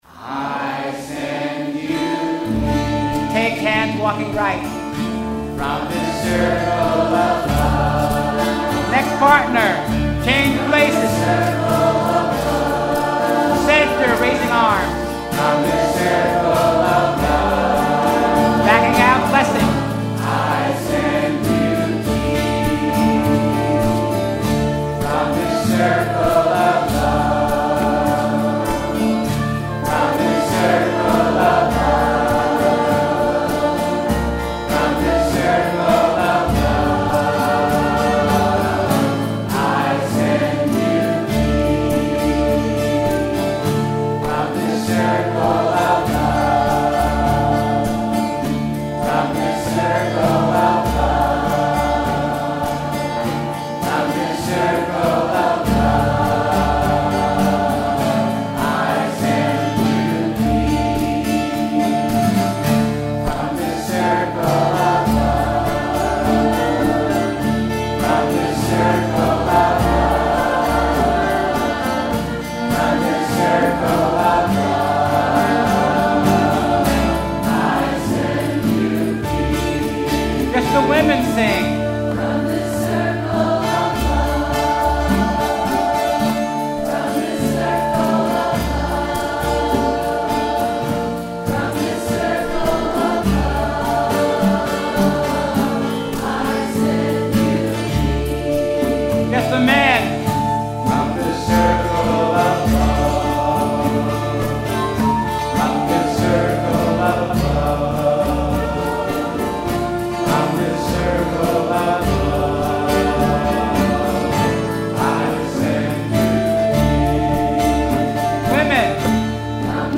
NW Sufi Camp 2012